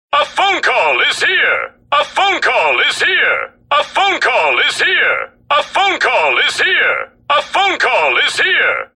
A Phone Call Is Here Sound Effect Free Download